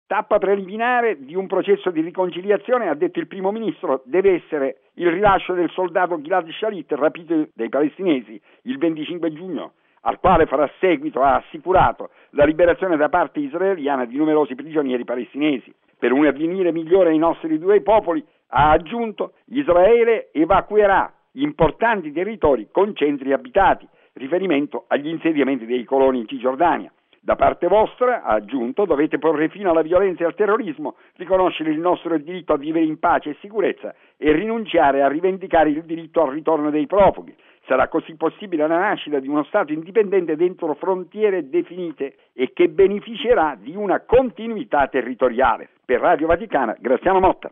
Nonostante sporadici scontri siano stati segnalati in Cisgiordania, ieri il primo ministro israeliano Ehud Olmert ha proposto ai palestinesi una ripresa del dialogo. Il servizio